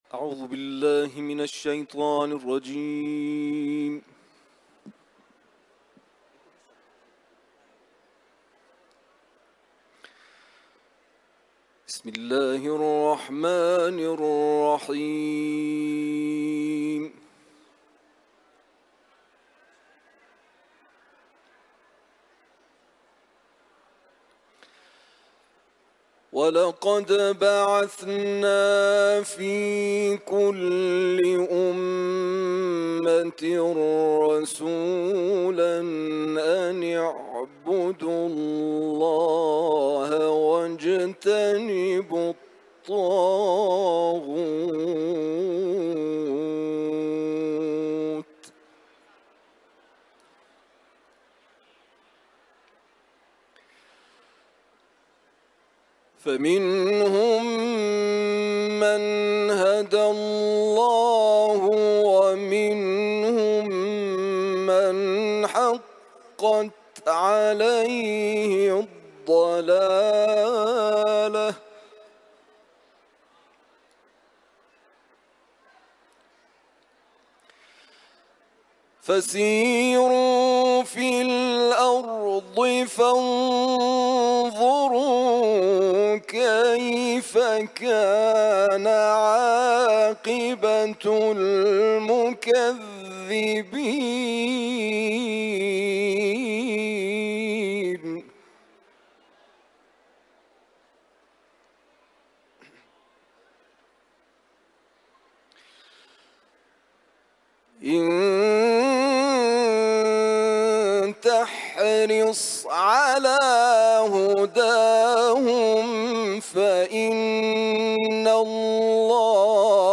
تلاوت قرآن ، سوره نحل ، حرم مطهر رضوی